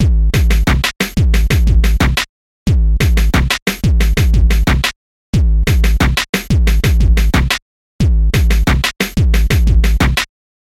描述：疯狂捣毁的嘻哈套装，随机的声音。
Tag: 90 bpm Weird Loops Drum Loops 1.79 MB wav Key : Unknown